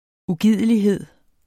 Udtale [ uˈgiðˀəliˌheðˀ ]